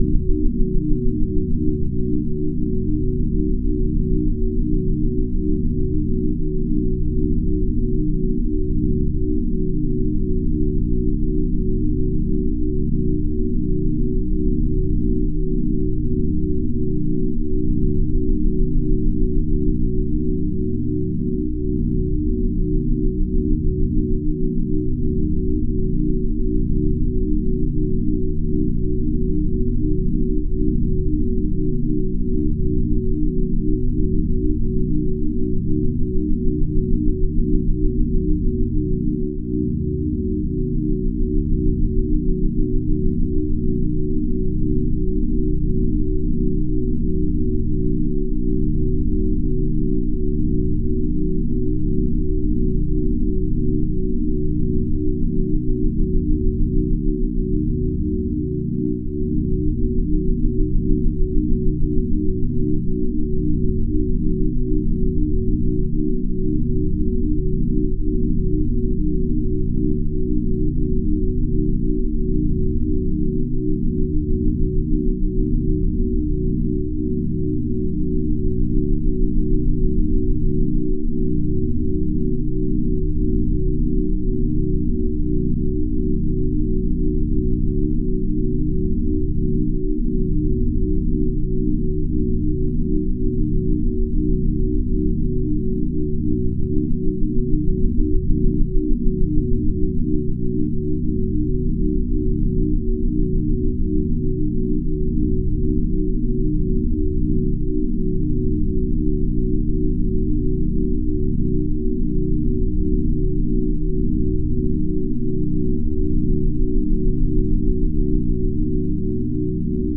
Schumann Frequencies.
This sound file contains the strongest Schumann frequencies in the Pure Audio .FLAC format.
Schumman Resonance.flac